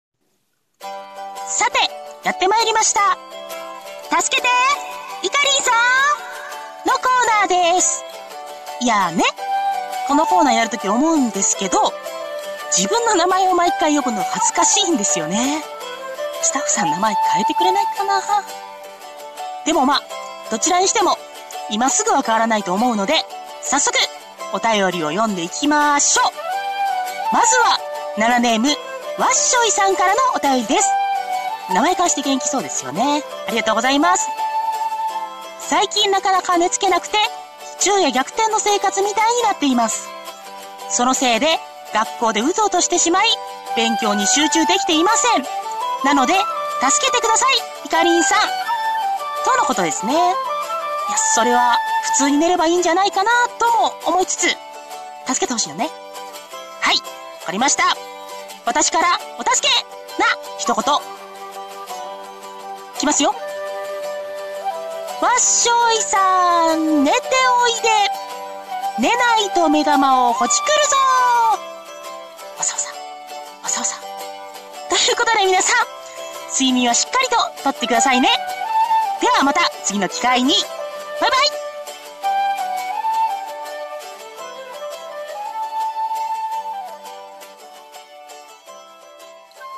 【一人声劇 朗読 ラジオ】助けて〇〇さーん！！